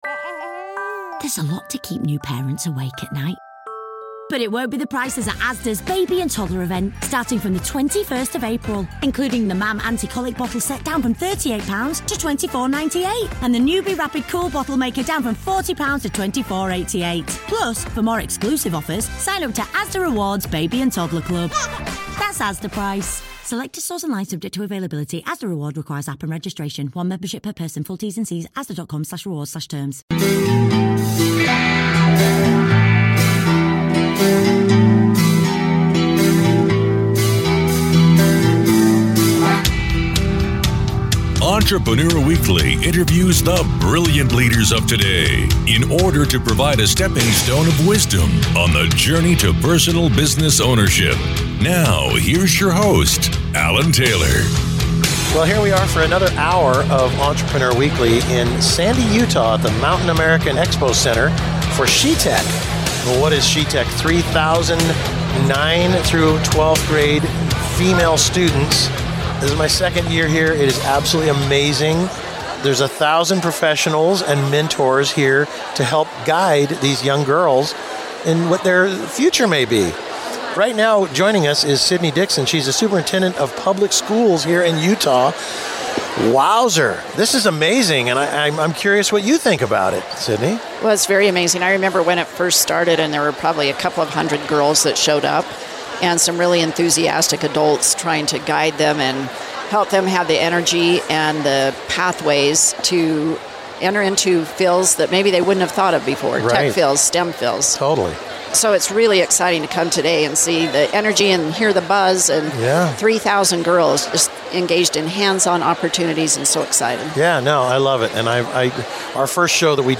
She Tech Event 2025 Mountain America Expo Center II